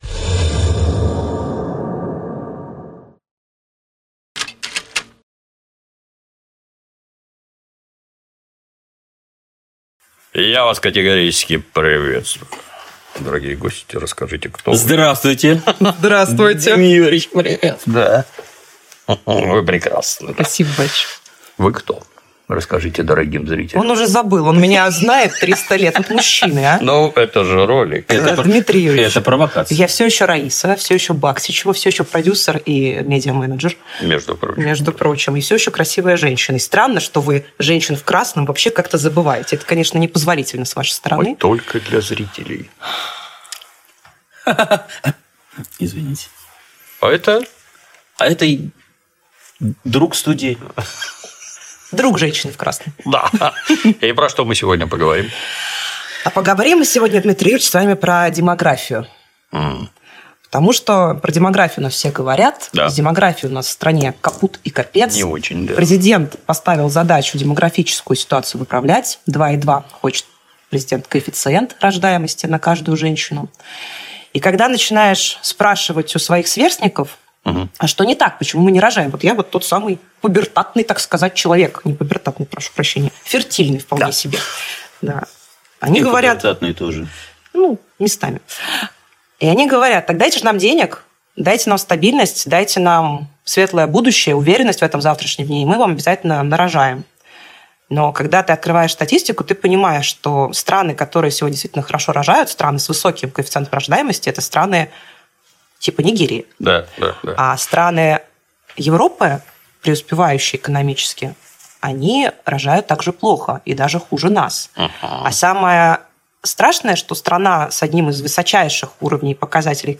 interview_demography.mp3